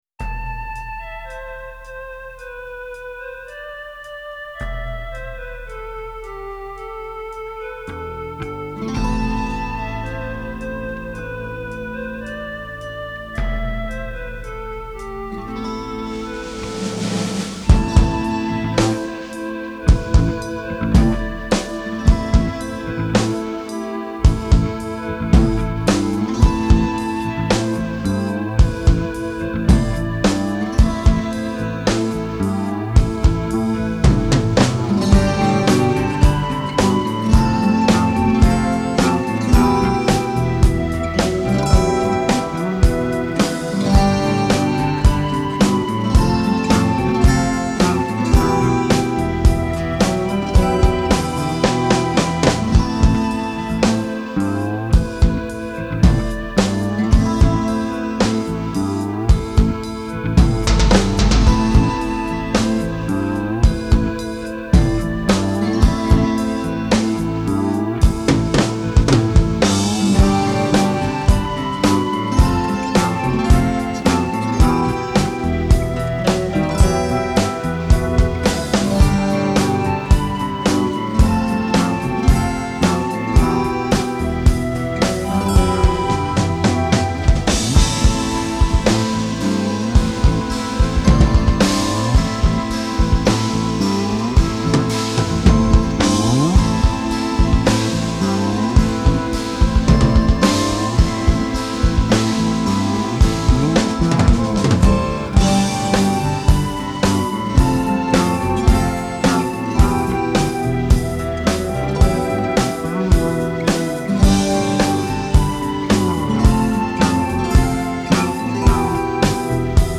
Genre : Punk, New Wave